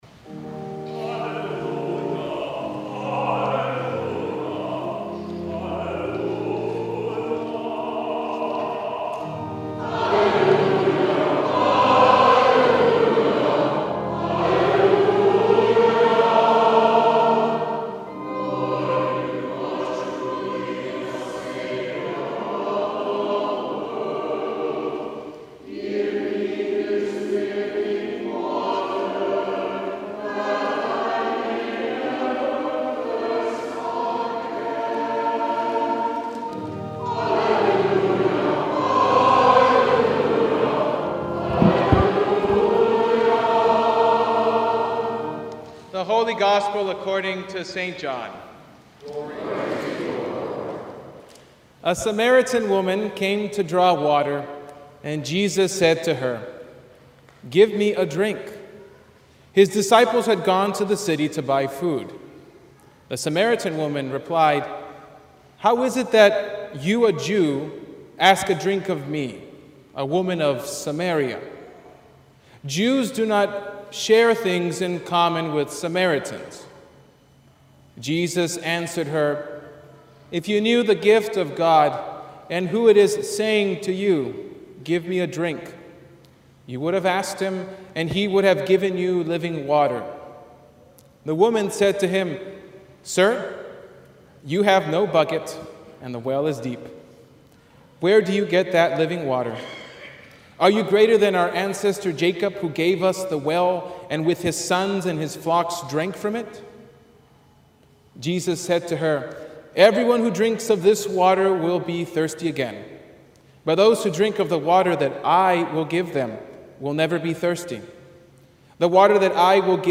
Sermon from RIC Sunday 2024